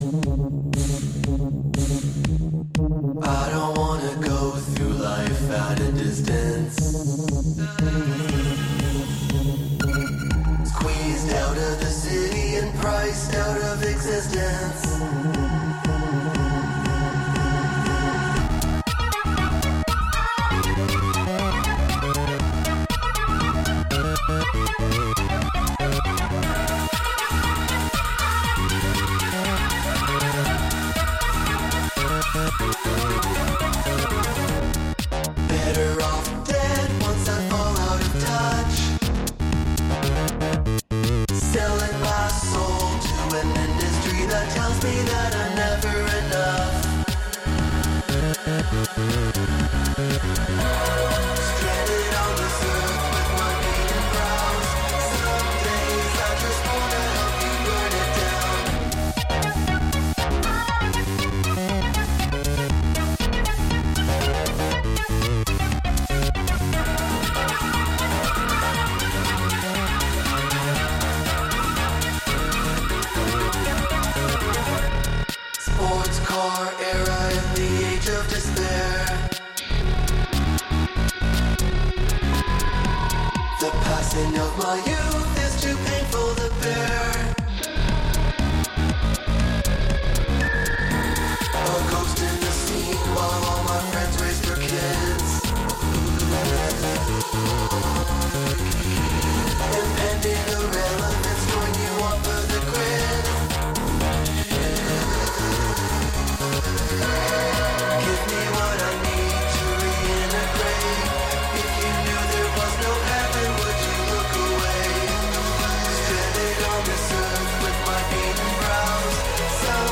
Serving up a super special fundrive show, spinning 100% Canadian music featuring techy rollers, garage, bass, new music and some fun remixes!